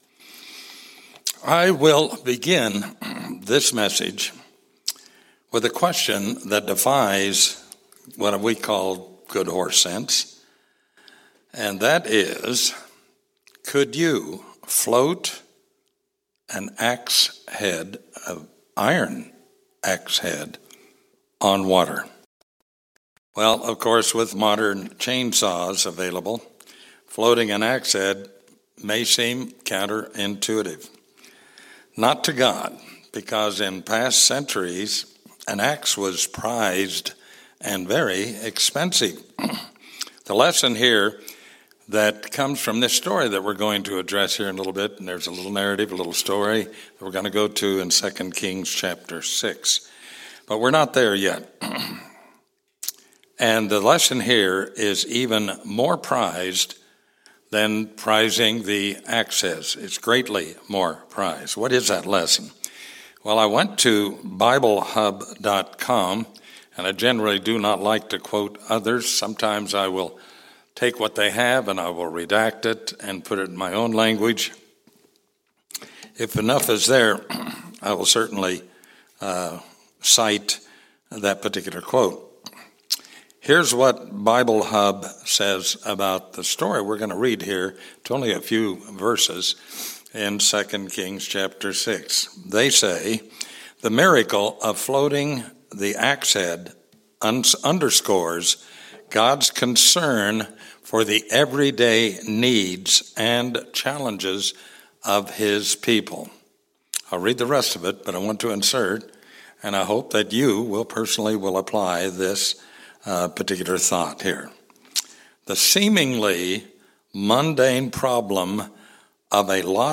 Given in Atlanta, GA